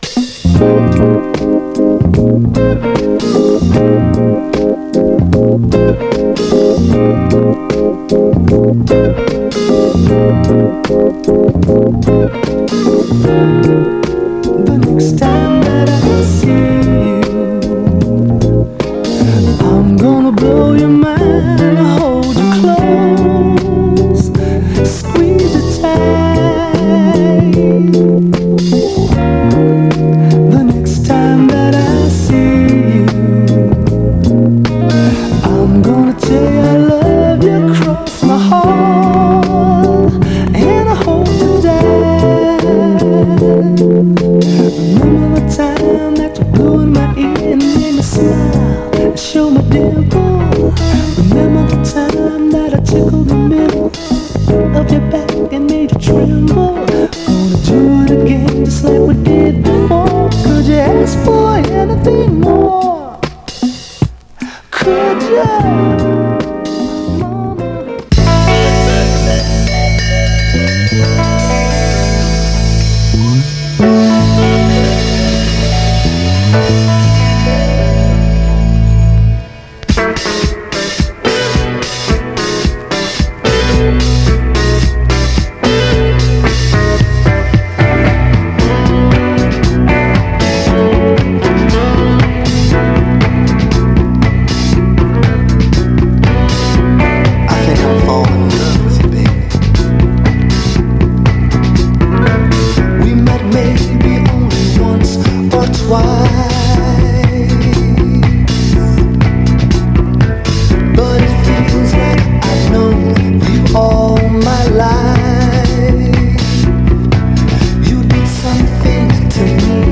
"やCommon "Next Time"でサンプリングした、スウィート・ソウル名作です！
疾走感あるソウル・ダンサー
※試聴音源は実際にお送りする商品から録音したものです※